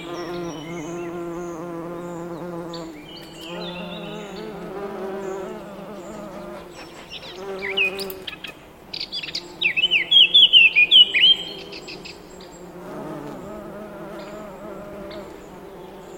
• blackbirds and bumblebees.wav
blackbirds_and_bumblebees_rVT.wav